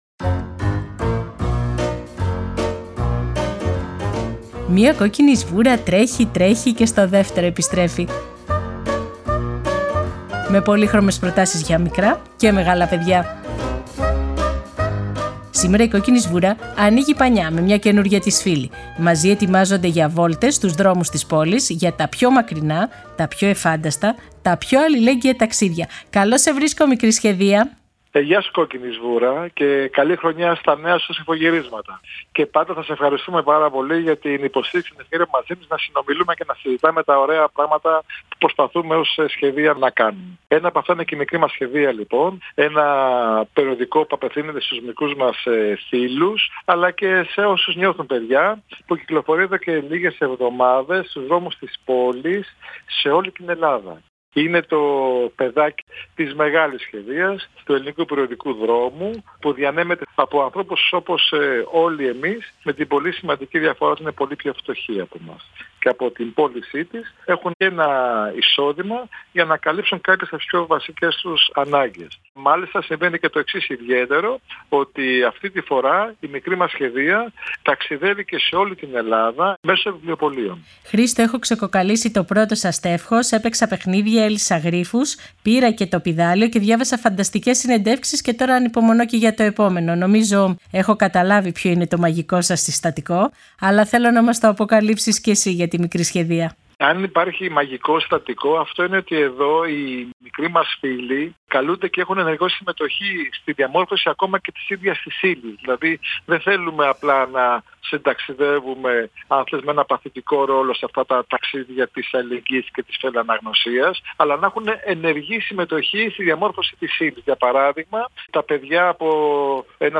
Σήμερα η Κόκκινη Σβούρα μαζί με μικρά και μεγάλα παιδιά διαβάζει το πρώτο τεύχος της Μικρής Σχεδίας για ένα καλύτερο και πιο συμπεριληπτικό κόσμο.